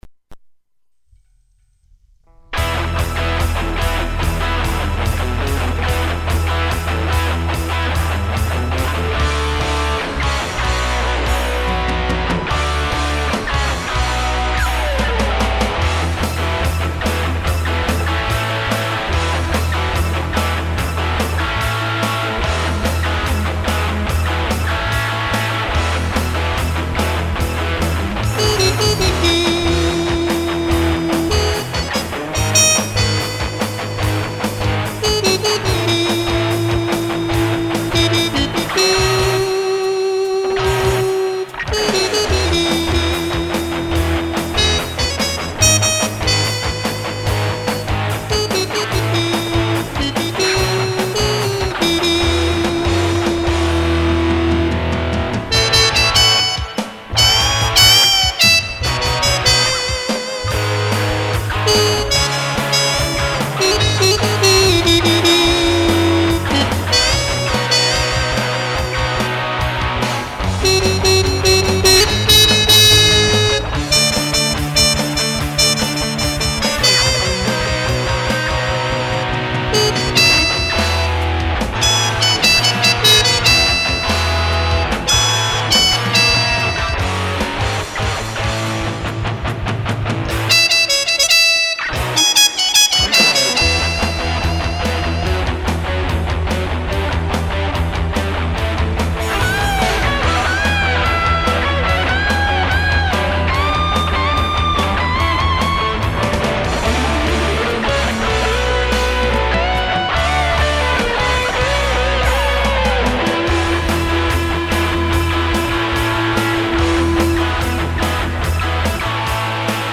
（Guitar,Bass and Programming)
下手の横好きで、一人でコソコソ演っているギターで、何となく録音した音を晒してしまいます。
その２．ギタシン不調につきスイマセン。